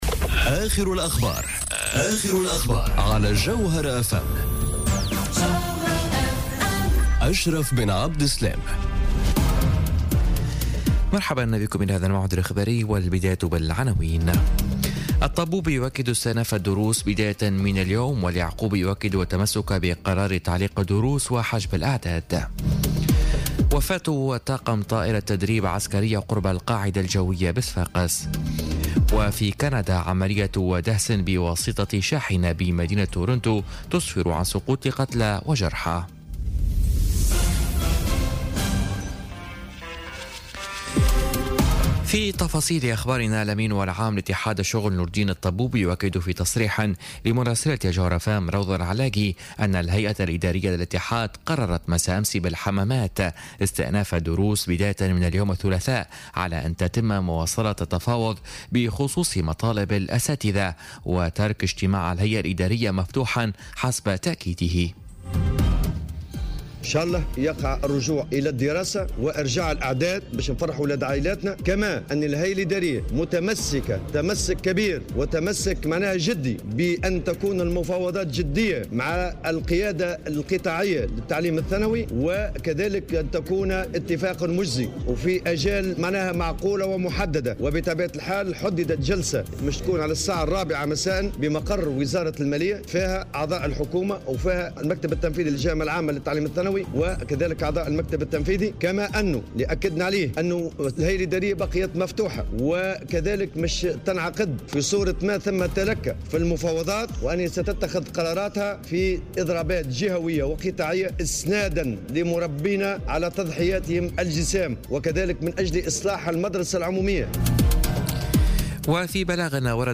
نشرة أخبار منتصف الليل ليوم الثلاثاء 24 أفريل 2018